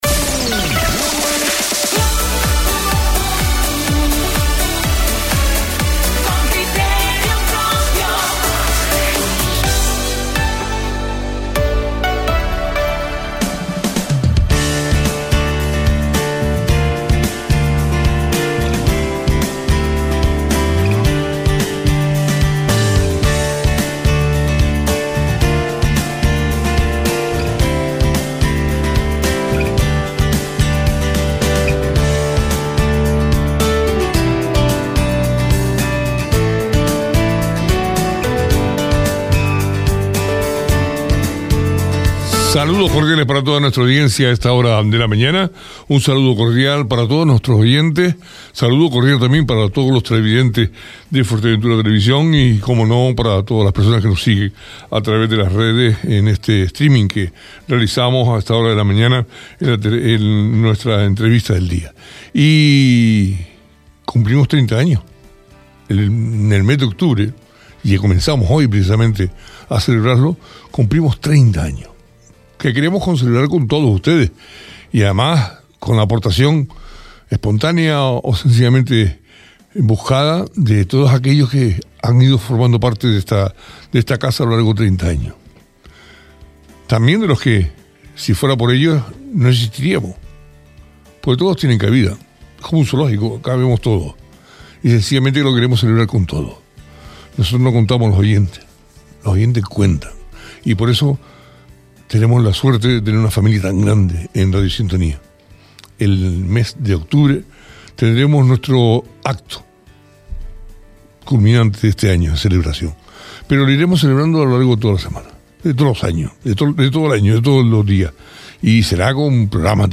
La presidenta del Cabildo de Fuerteventura, Lola García es la primera invitada a la página de política de Radio Sintonía en 2025.